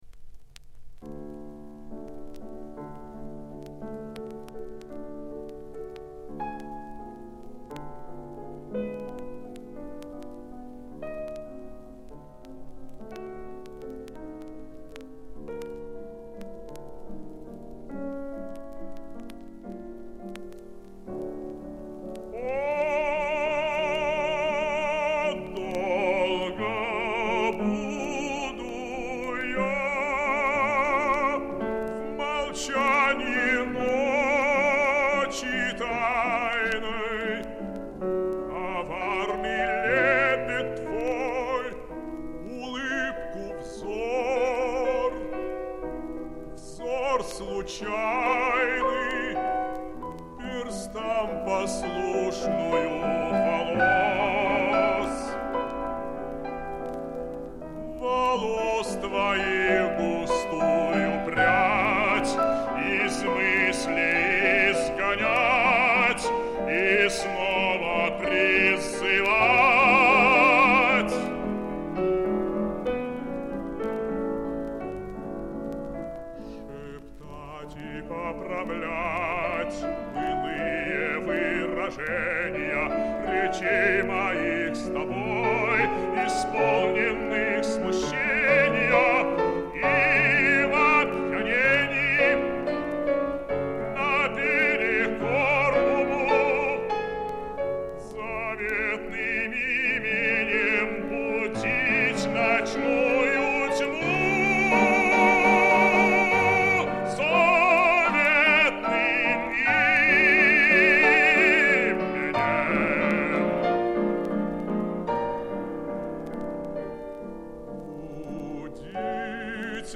Романс